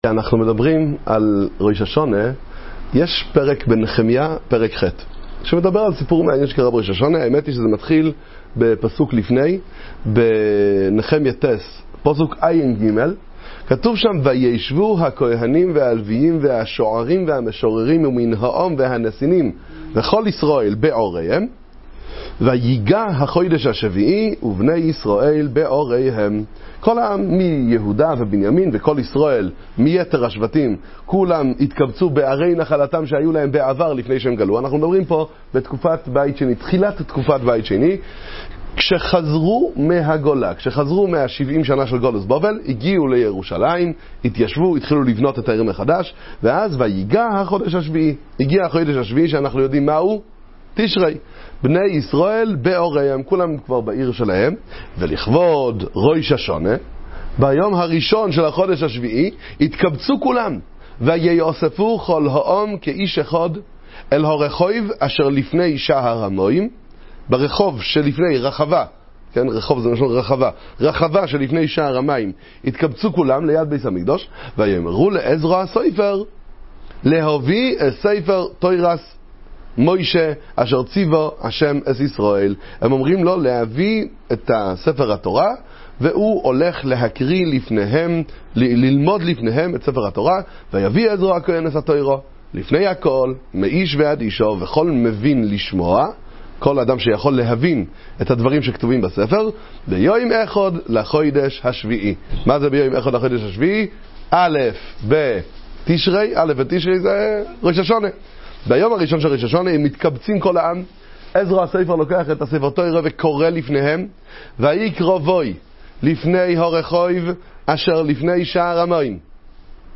שיעור על ראש השנה